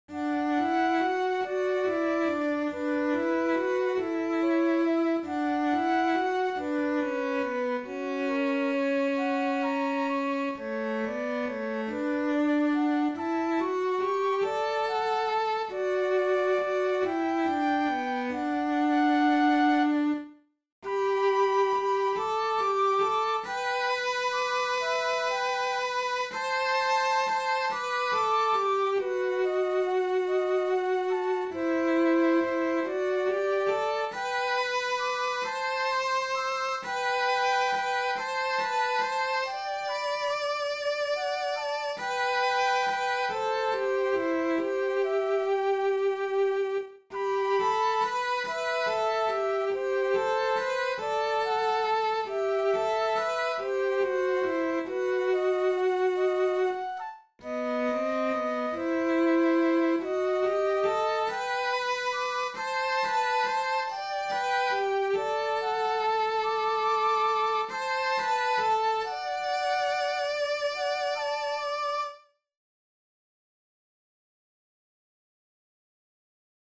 Paalla-vihrean-maan-sello-ja-huilu.mp3